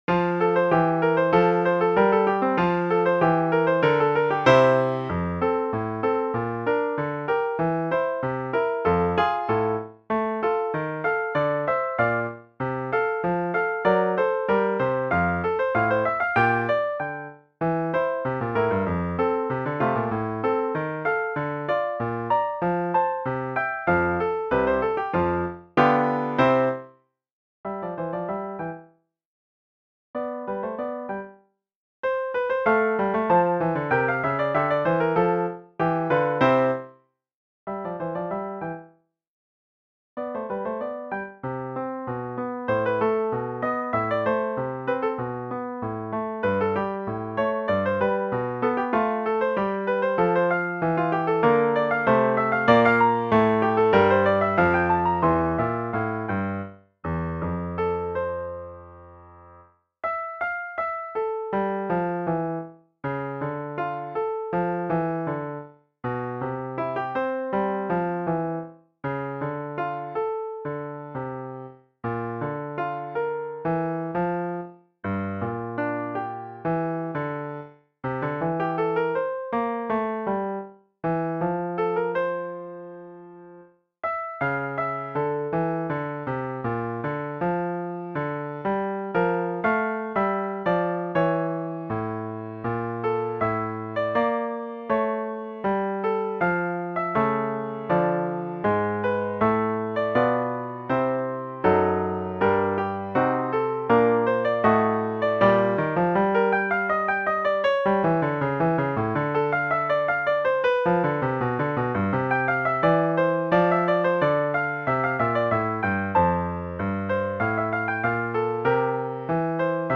UNE-VIE-EN-TROMPETTE-PIANO.mp3